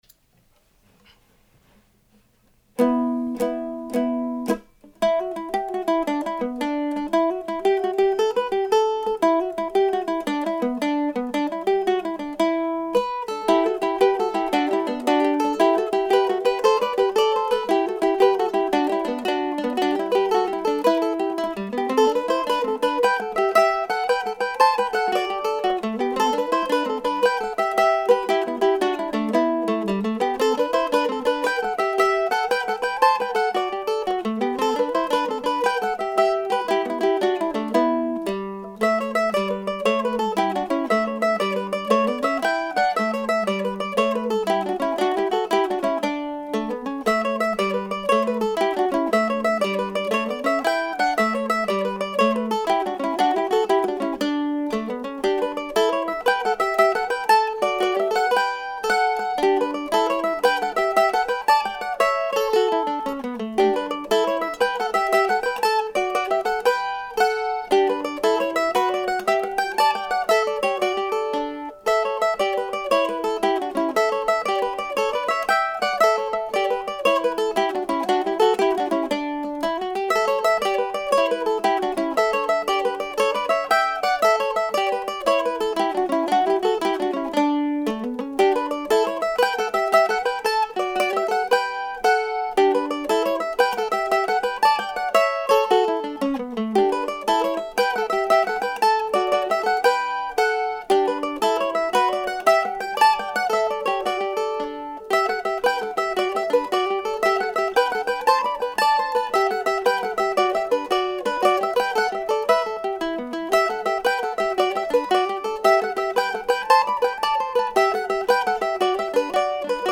So these two jigs, still individually unnamed, popped out while my head was still full of dance weekend music.